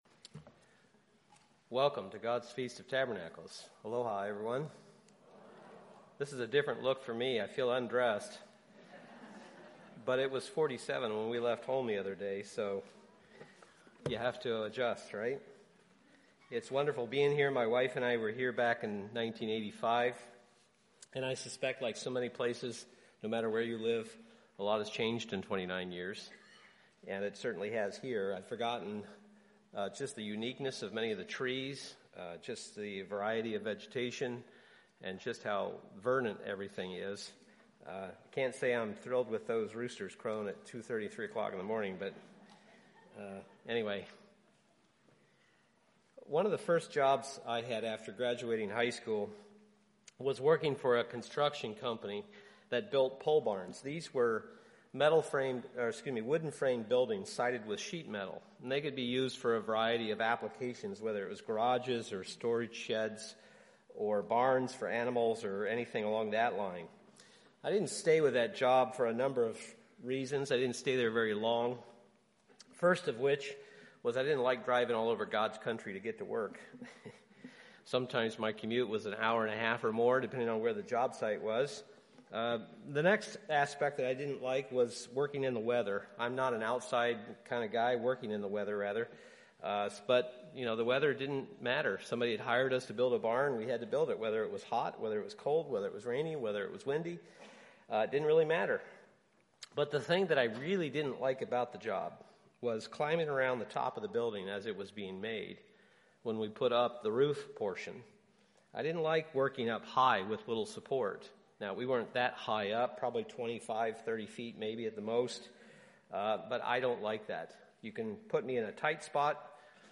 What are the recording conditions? This sermon was given at the Lihue, Hawaii 2014 Feast site.